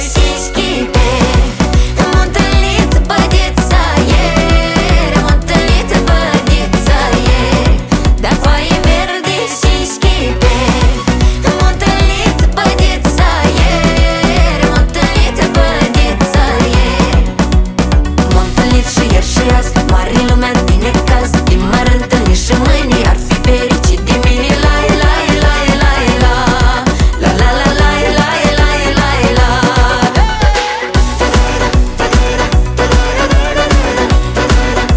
Folk-Rock
Жанр: Рок / Фолк